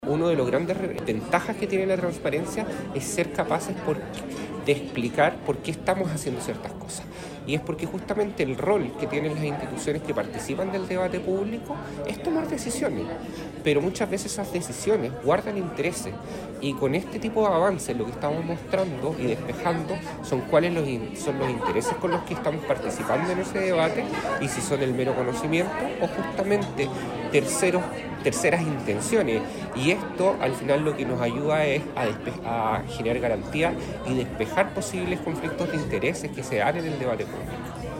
Con una fuerte apuesta por el fortalecimiento de la confianza pública y el acceso abierto a la información, las universidades agrupadas en la Red G9 celebraron el pasado viernes 28 de marzo el seminario “Transparencia en Educación Superior: avances y desafíos” en la Unidad de Santiago de la Universidad de Concepción (UdeC).
En la segunda parte del seminario, el panel de discusión profundizó en los avances y desafíos futuros para el sistema de educación superior.